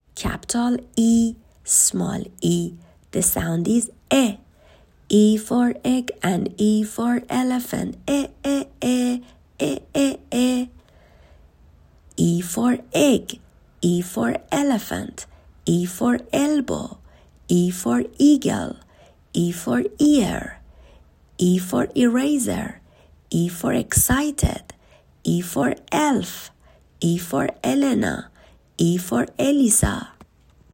حروفی که بچه ها یاد گرفتند تا اینجا رو در قالب چند ویس ، گذاشتم.
حرف Ee ، صداش و لغاتش